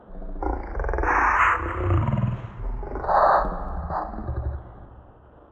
Category 🐾 Animals
ambience animal atmosphere breath dark electronic growl human sound effect free sound royalty free Animals